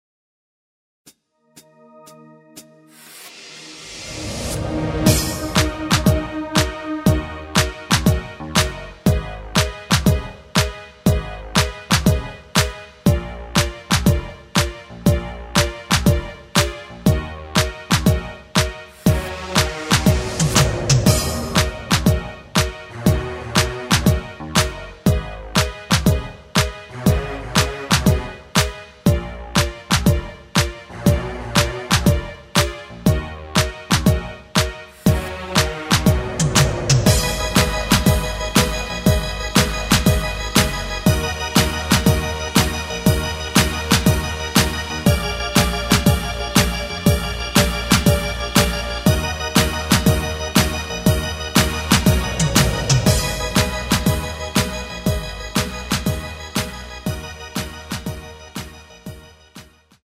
Ebm
앞부분30초, 뒷부분30초씩 편집해서 올려 드리고 있습니다.
중간에 음이 끈어지고 다시 나오는 이유는